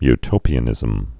(y-tōpē-ə-nĭzəm)